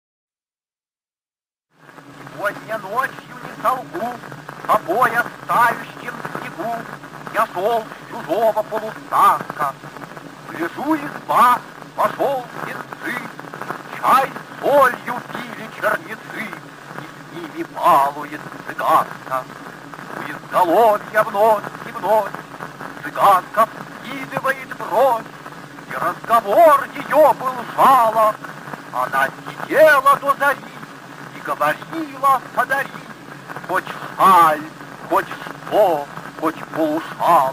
4. «Осип Мандельштам (читает автор) – Сегодня ночью, не солгу…» /
Mandelshtam-chitaet-avtor-Segodnya-nochyu-ne-solgu..-stih-club-ru.mp3